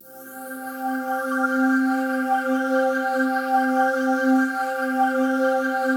PAD 49-3.wav